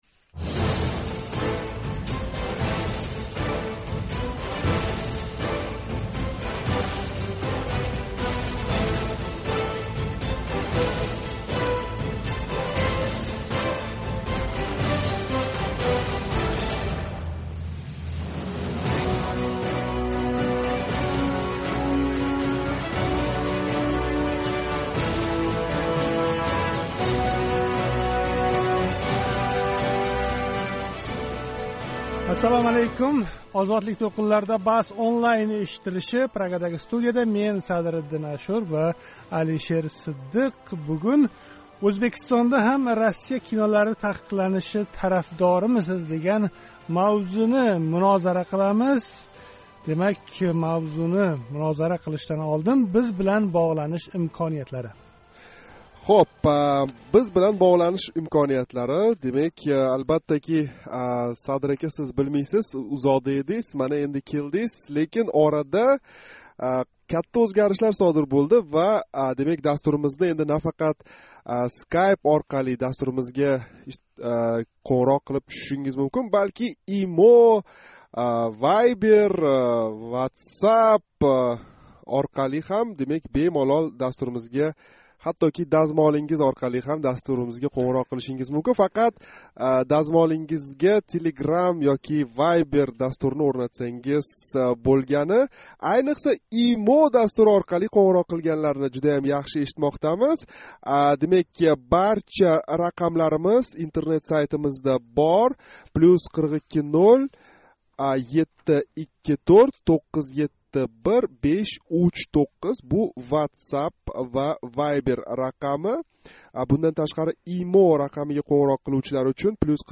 BahsOnlineнинг ана шу саволга ажратилган бугунги мулоқотига қўшилмоқчи бўлсангиз, Тошкент вақти билан кечки соат 7 дан бошлаб OzodlikOnline, OzodSkype, OzodOvoz Skype манзилларига қўнғироқ қилинг¸ ëхуд IMO¸WhatsApp ва Viber рақамларимизга боғланинг.